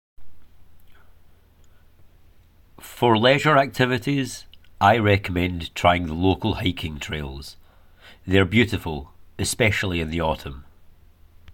6. GB accent (Scotland): Leisure